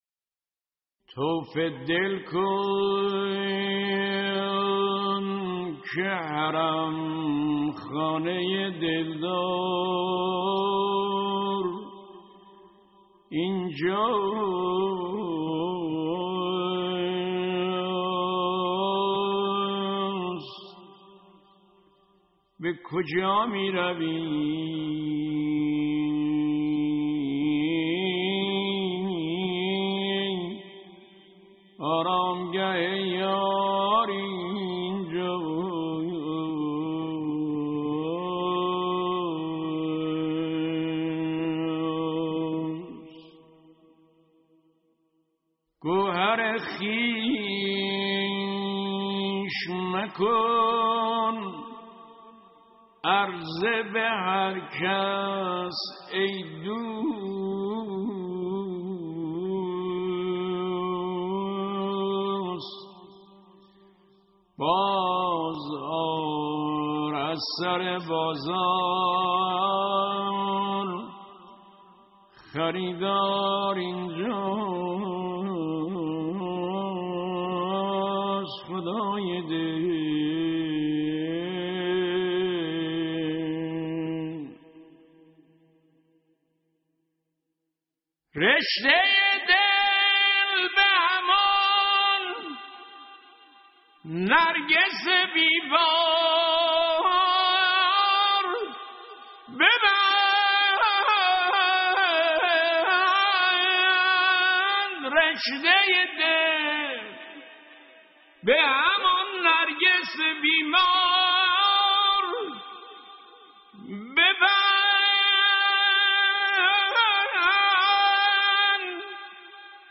تک خوانی